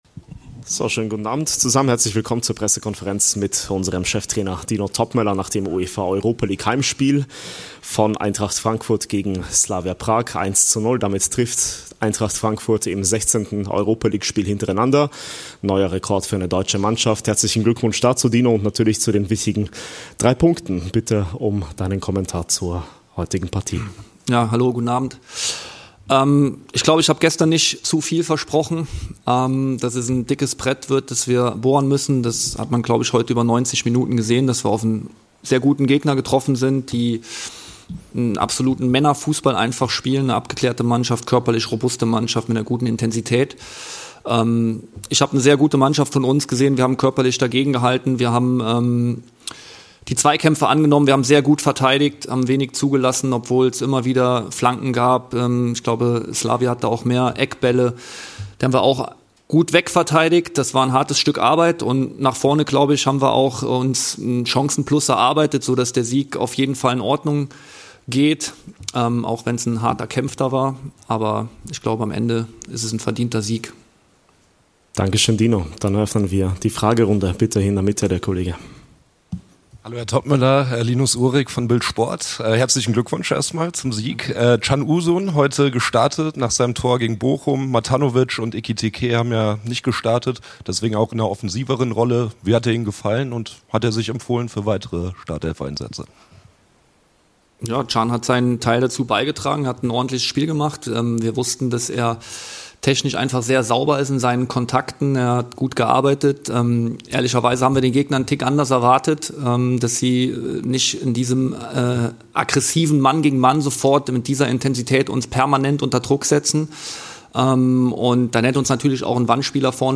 Die Pressekonferenz nach unserem Europa-League-Heimsieg gegen
Slavia Prag mit unserem Cheftrainer Dino Toppmöller.